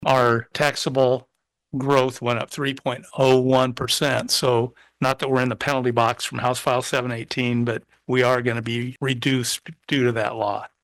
Johnson says the 3.01 percent increase narrowly slips into the range where the state will limit the county’s tax collections in FY26.